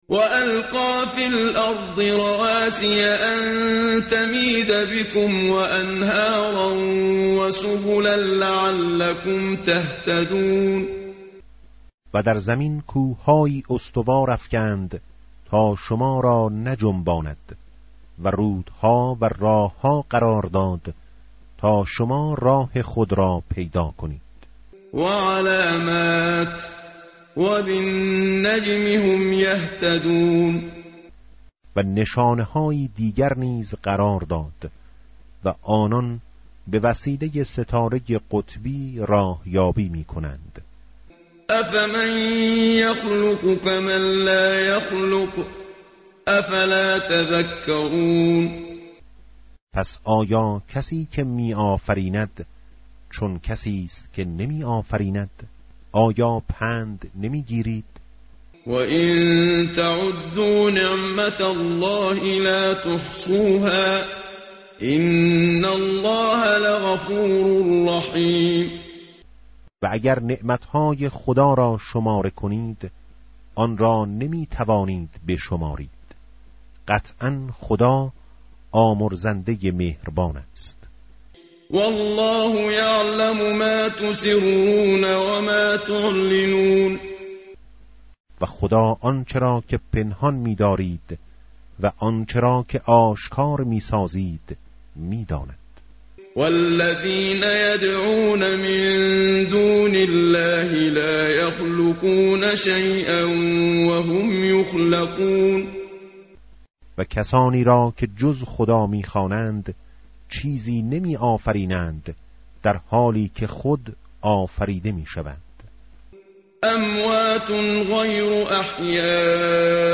متن قرآن همراه باتلاوت قرآن و ترجمه
tartil_menshavi va tarjome_Page_269.mp3